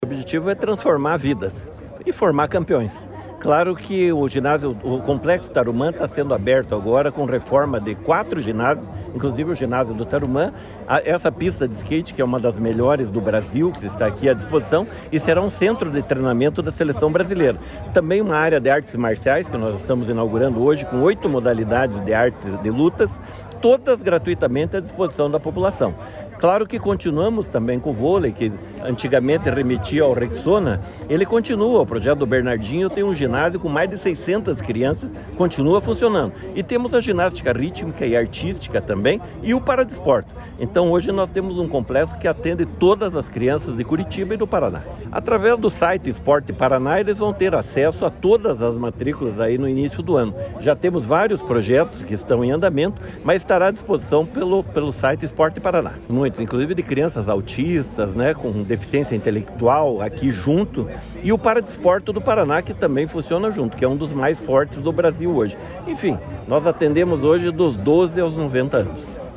Sonora do secretário Estadual do Esporte, Hélio Wirbiski, sobre o novo Centro de Treinamento do Skate, inaugurado no Complexo Tarumã